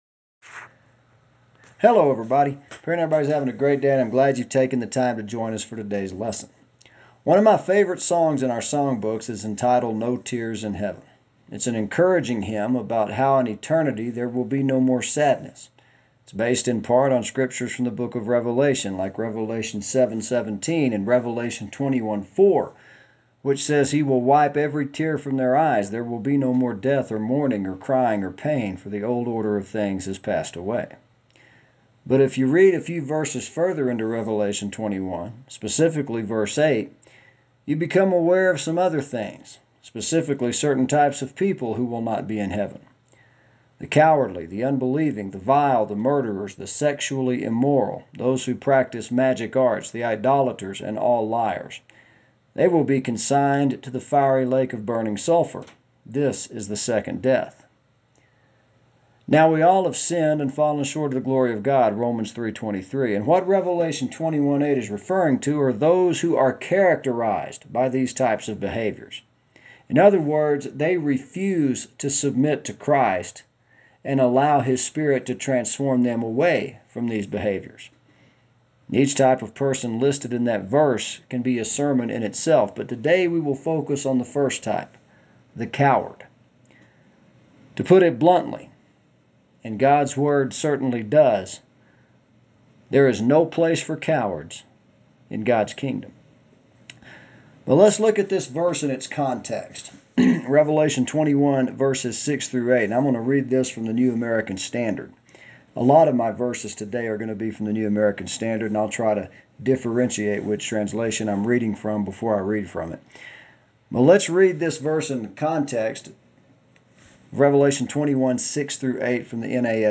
Each type of person listed can be a sermon in itself but this Sunday we will focus on the first type, the coward.